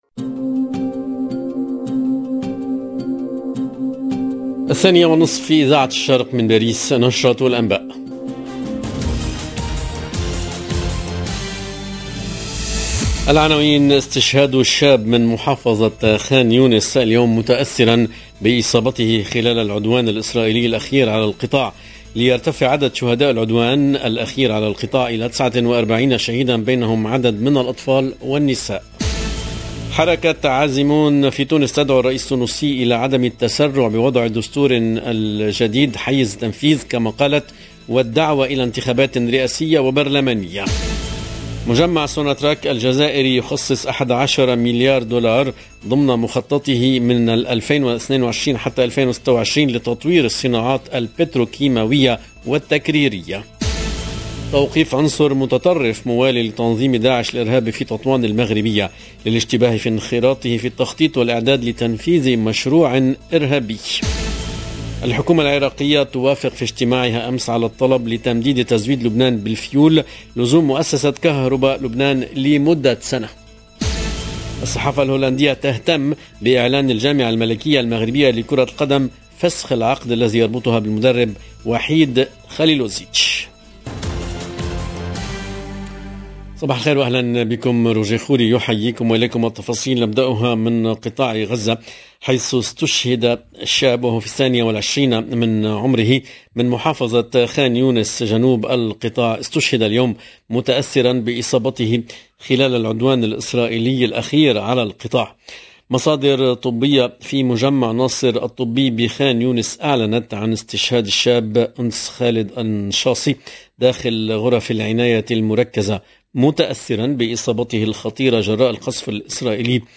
LE JOURNAL EN LANGUE ARABE DE LA MI-JOURNEE DU 12/08/22